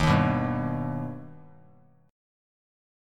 D#+ Chord
Listen to D#+ strummed